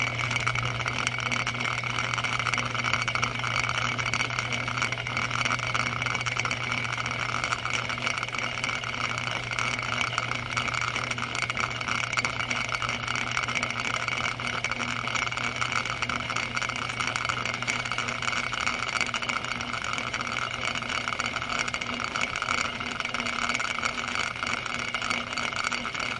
金属加工厂" 机床 金属切割器 磨刀机 滚筒 关闭6
Tag: 切割机 关闭 机器 金属 粉碎机